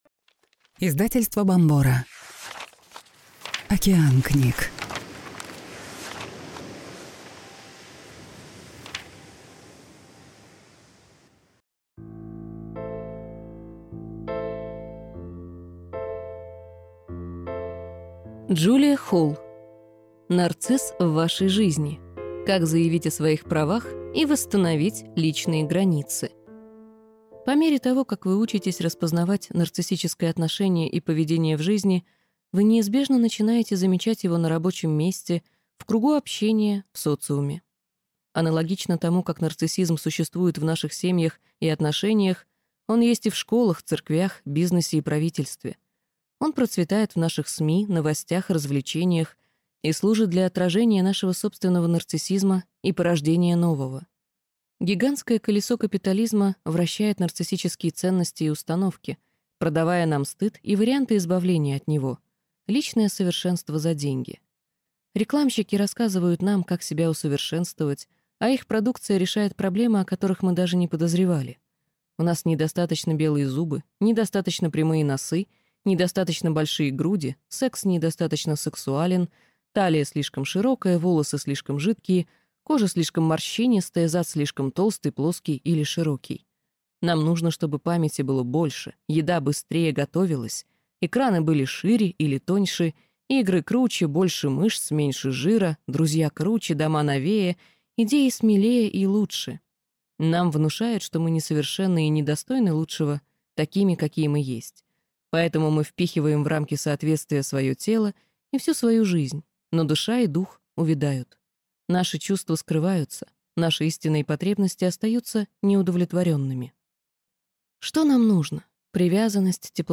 Аудиокнига Нарцисс в вашей жизни. Как заявить о своих правах и восстановить личные границы | Библиотека аудиокниг